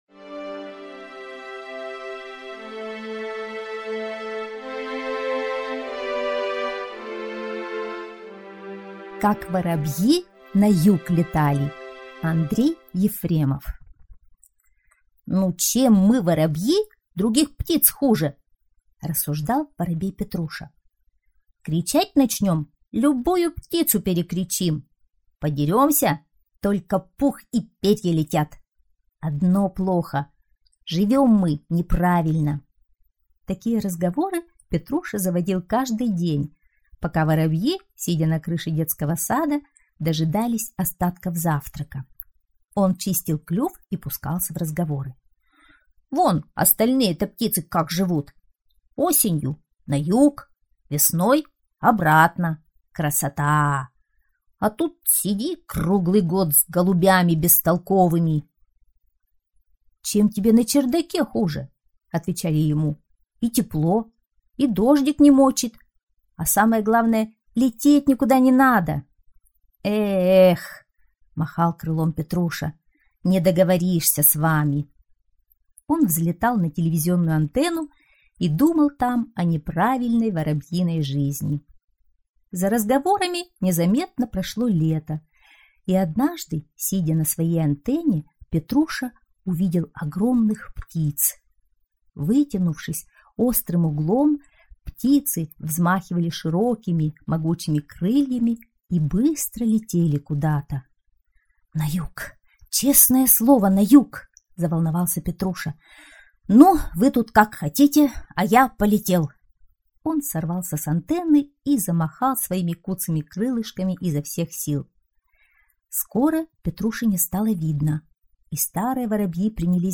Как воробьи на юг летали - аудиосказка Ефремова - слушать онлайн